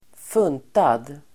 Ladda ner uttalet
Uttal: [²f'un:tad]